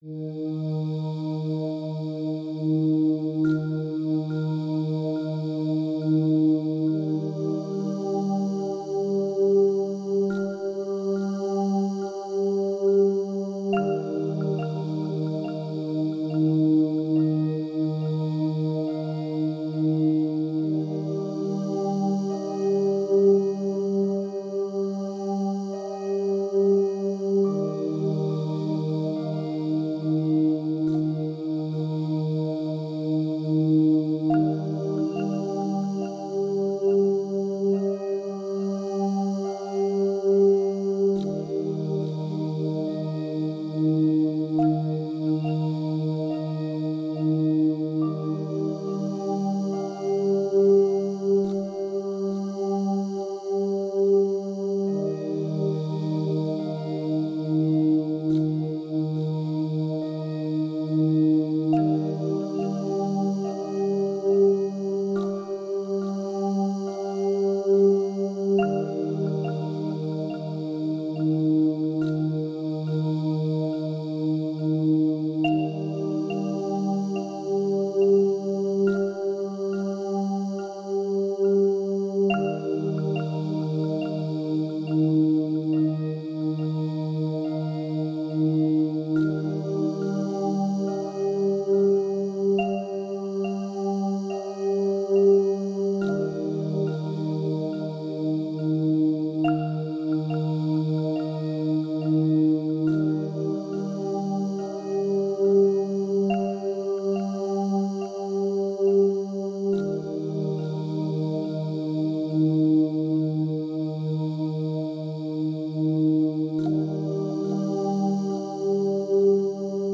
• Categoría: Calma y apaciguamiento, relajación
• Calidad: Alta